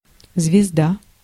Ääntäminen
US : IPA : [ˈspaɪ.dɚ]